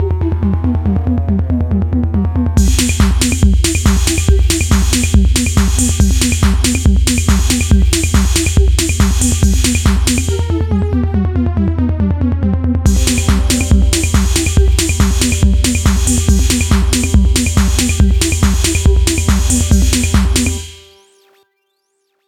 بیت الکترو از خودم
تقریبا میشه گفت واسه پارتی هستش